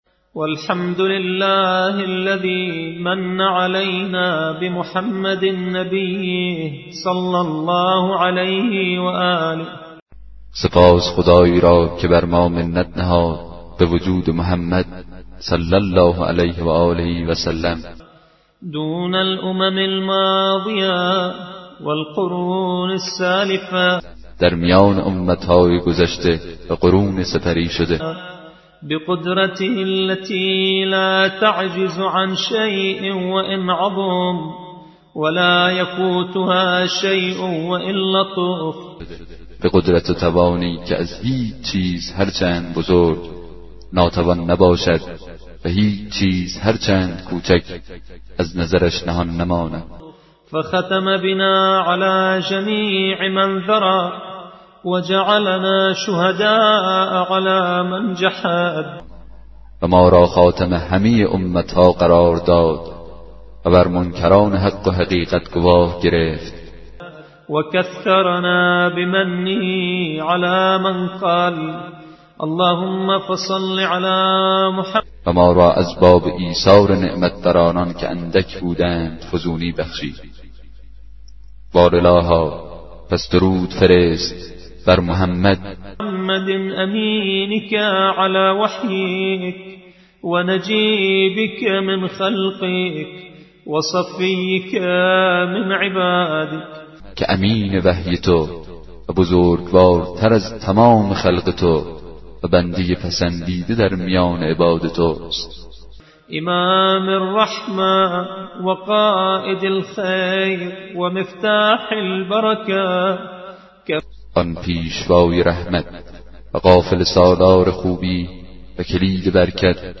کتاب صوتی دعای 2 صحیفه سجادیه